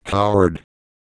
Worms speechbanks
coward.wav